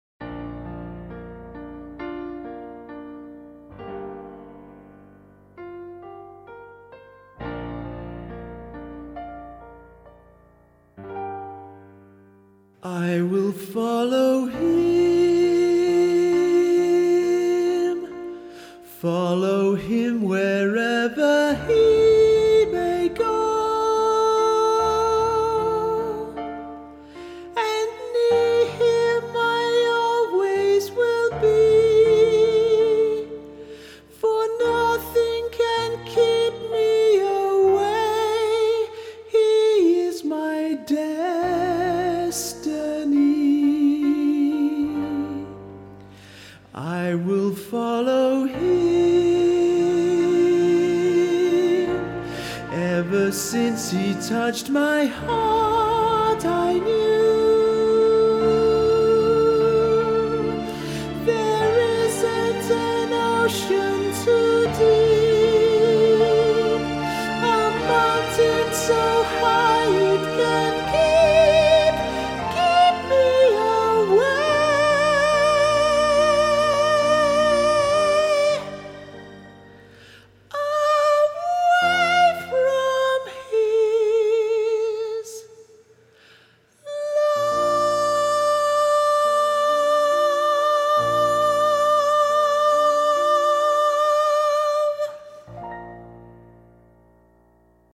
Choir
Sister Act - High Part
I-Will-Follow-Him-intro-HIGH-PART.mp3